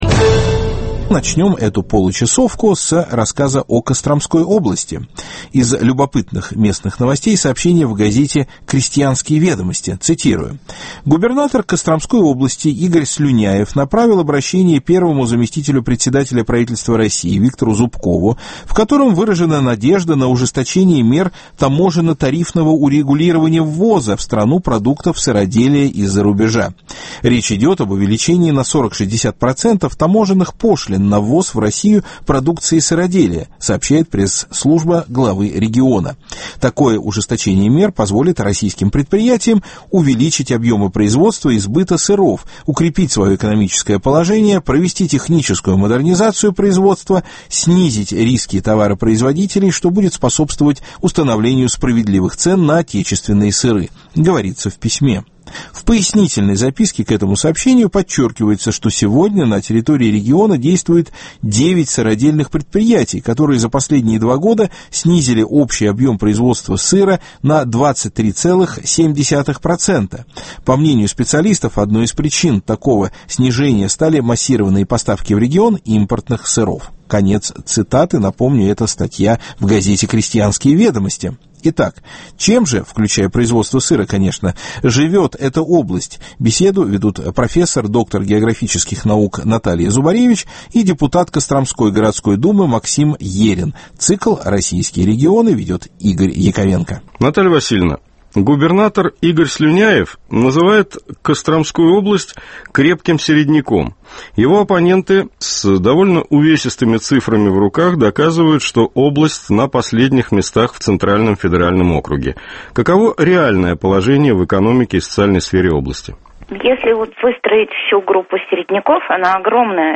Проблемы Костромской области – очередная беседа из цикла «Российские регионы»